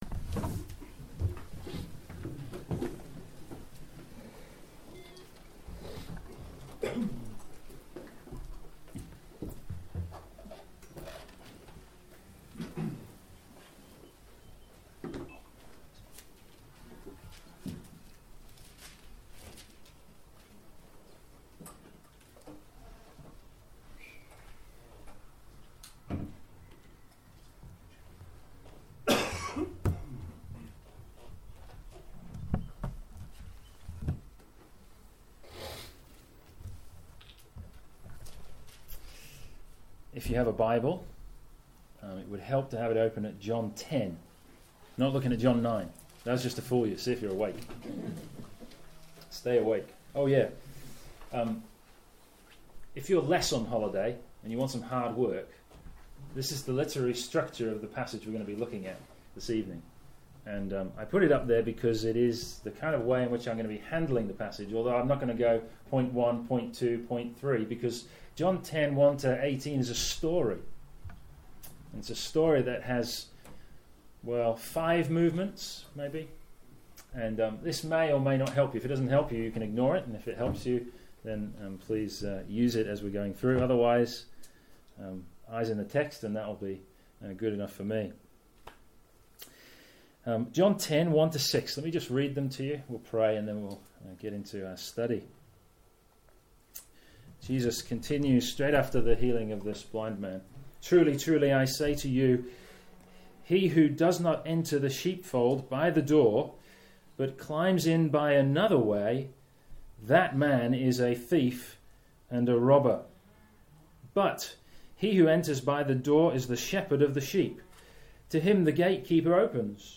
on 13 May 2016 at the Church Weekend.